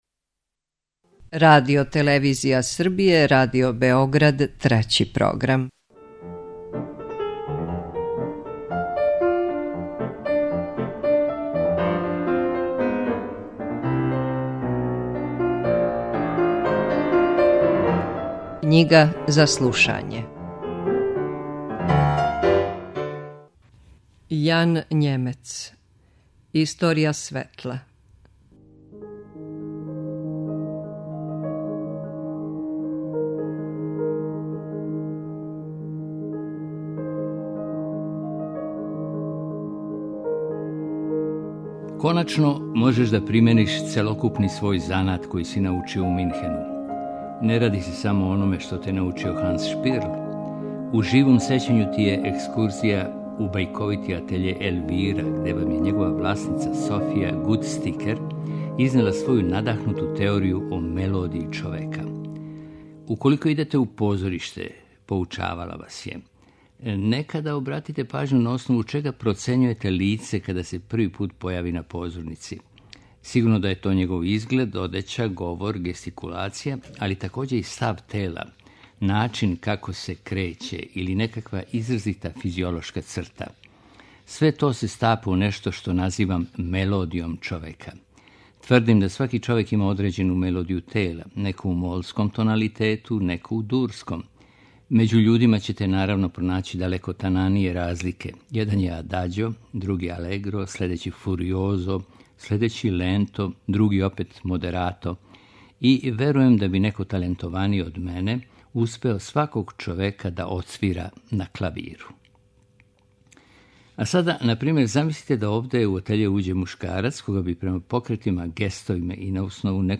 Knjiga za slušanje